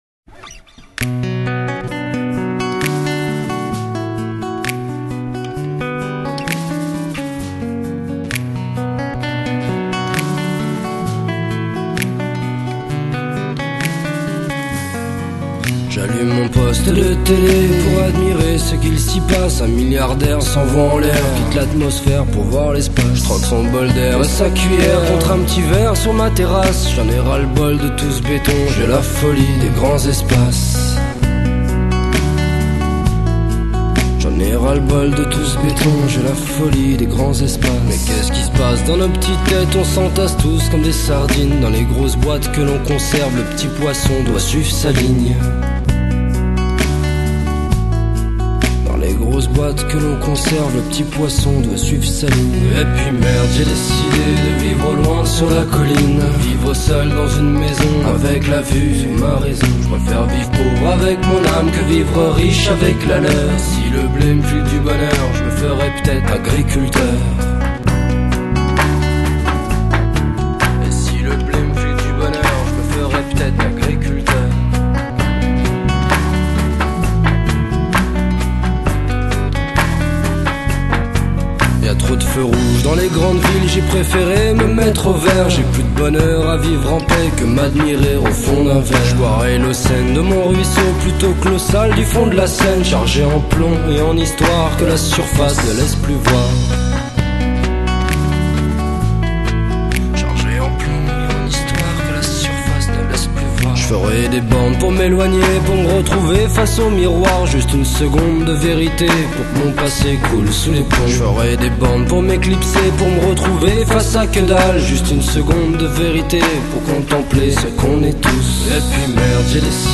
Puis plus récemment, il passe du rap à la chanson à texte.